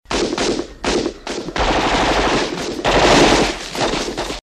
• PROGRESSIVE GUNFIRE.wav
PROGRESSIVE_GUNFIRE_jfj.wav